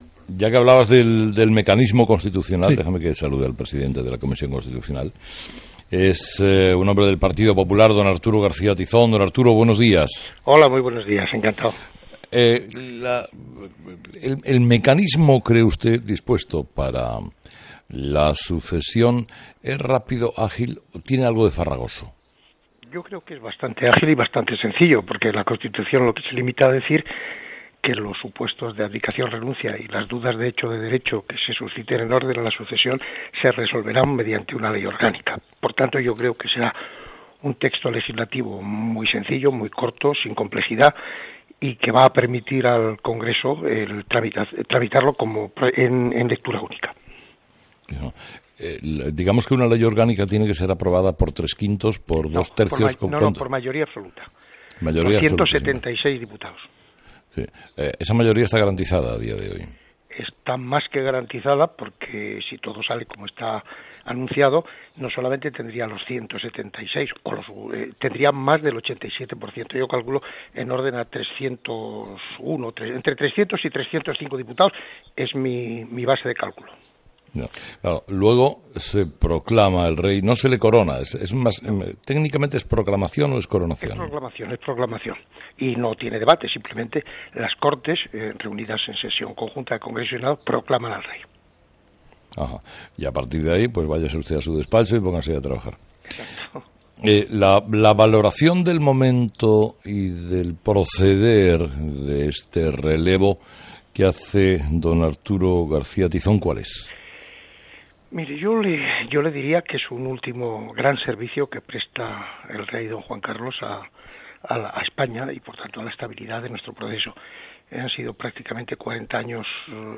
Entrevista a Arturo García Tizón